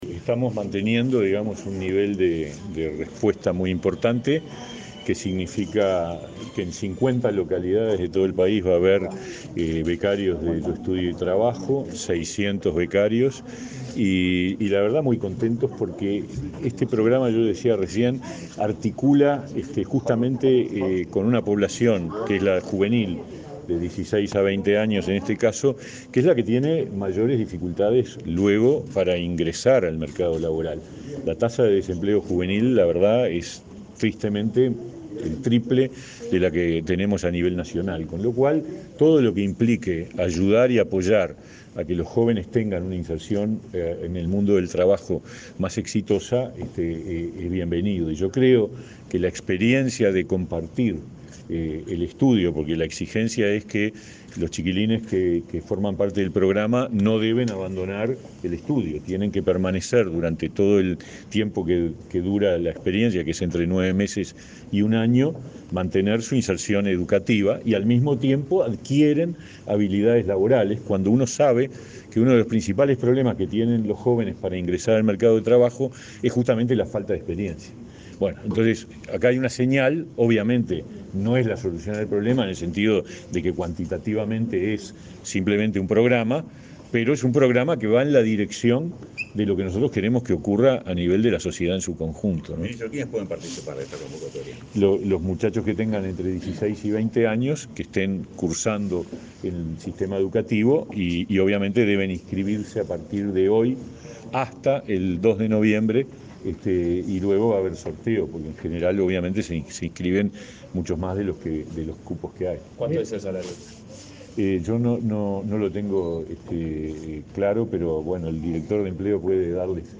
Declaraciones del ministro de Trabajo, Pablo Mieres
El ministro de Trabajo, Pablo Mieres, dialogó con la prensa luego de participar en la presentación de la 11.ª edición del programa Yo Estudio y